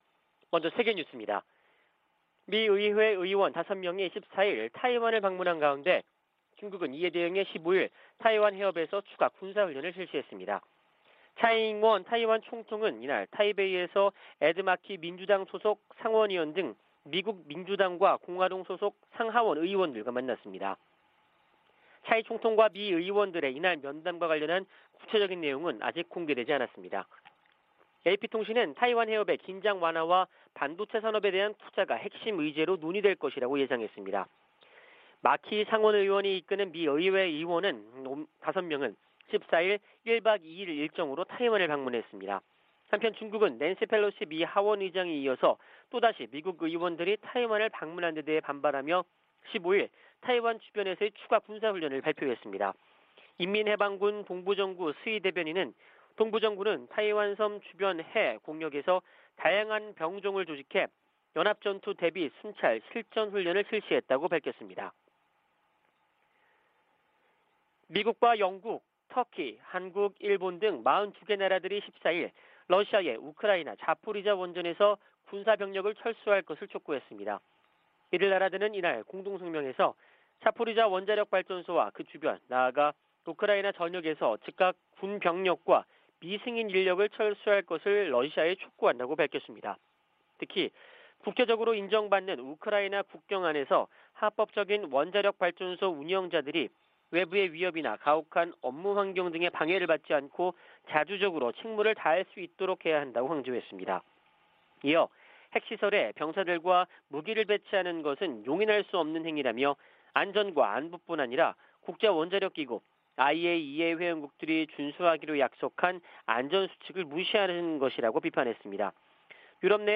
VOA 한국어 '출발 뉴스 쇼', 2022년 8월 16일 방송입니다. 윤석열 한국 대통령이 광복절을 맞아, 북한이 실질적 비핵화로 전환하면 경제를 획기적으로 개선시켜주겠다고 제안했습니다. 중국의 ‘3불 1한’ 요구에 대해 해리 해리스 전 주한 미국대사는 중국이 주권국가에 명령할 권리가 없다고 지적했습니다. 중국이 낸시 펠로시 미국 하원의장의 타이완 방문을 구실로 도발적인 과잉 반응을 지속하고 있다고 백악관 고위관리가 규탄했습니다.